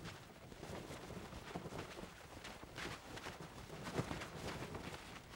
cloth_sail13.L.wav